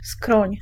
Ääntäminen
IPA : [ˈtemp(ə)l]